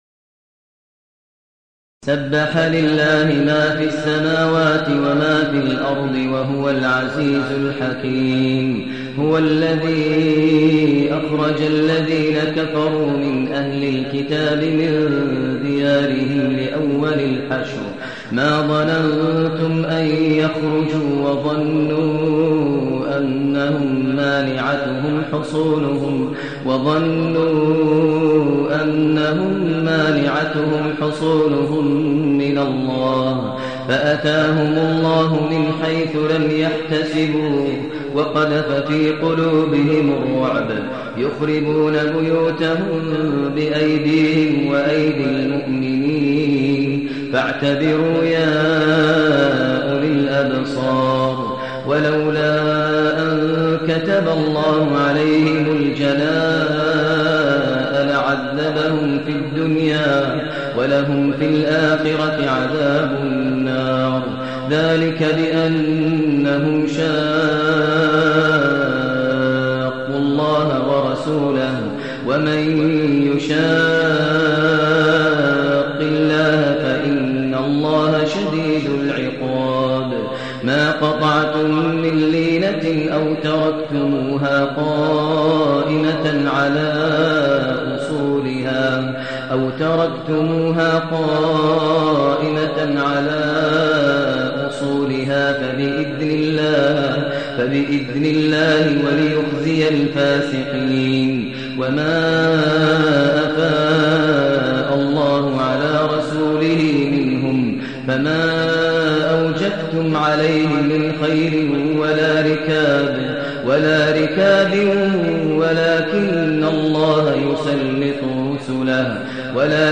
المكان: المسجد النبوي الشيخ: فضيلة الشيخ ماهر المعيقلي فضيلة الشيخ ماهر المعيقلي الحشر